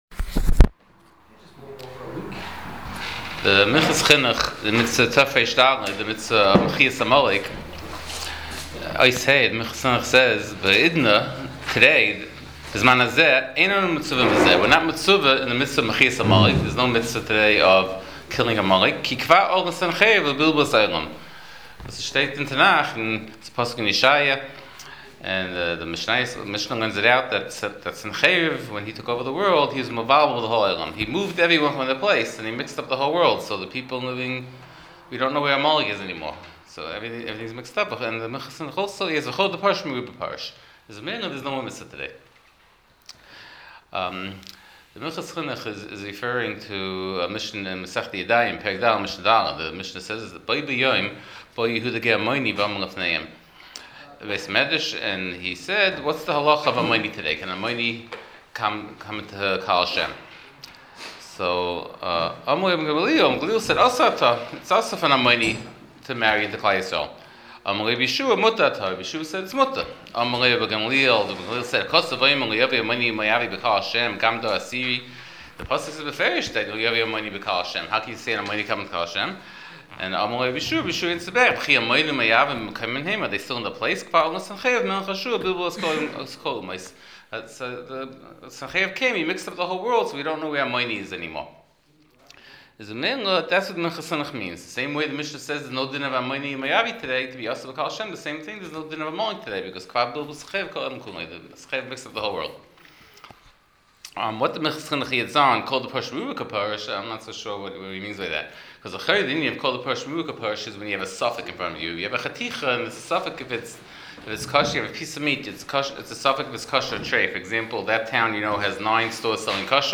Ubeinyan Parshas Zochur Veinyonei Purim Shiur provided courtesy of Madison Art Shop.